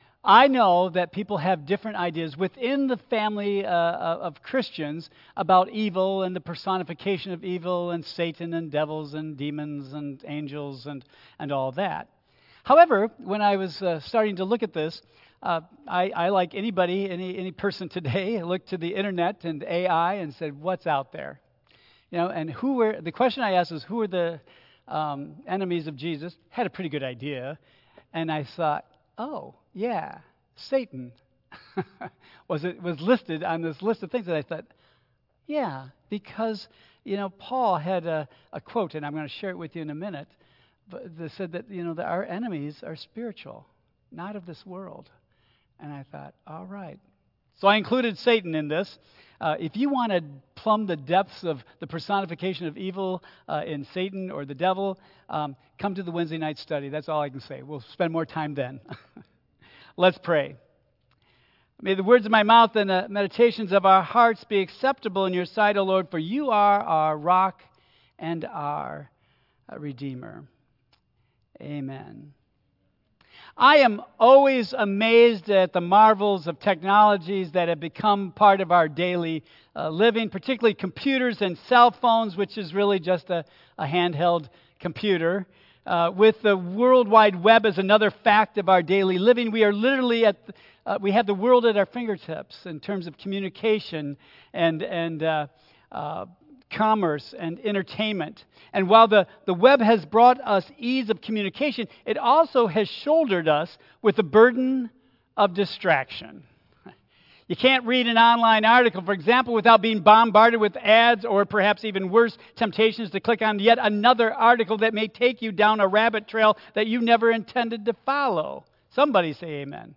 Lenten Message Series